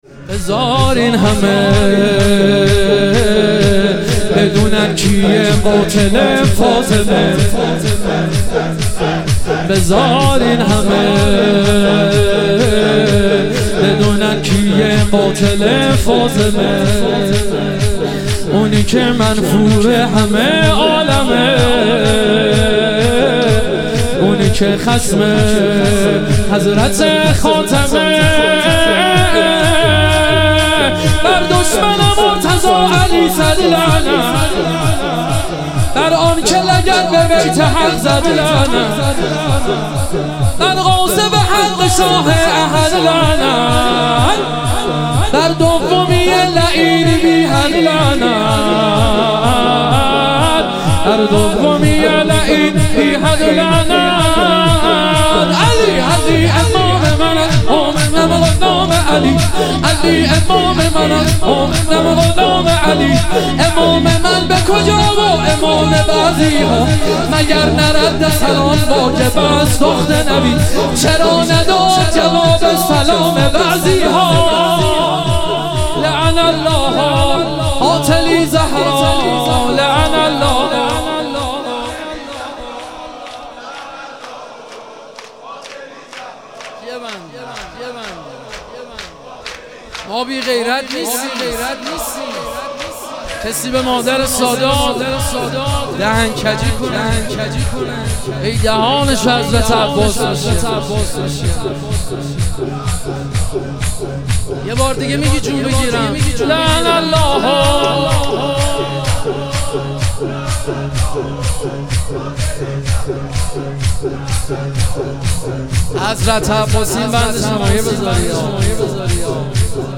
ایام فاطمیه دوم - شور